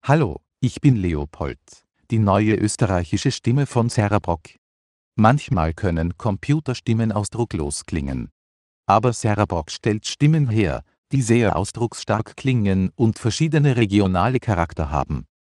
Österreichische Stimmen
Österreichische Stimme Leopold für Windows MS SAPI5, CereProc Ltd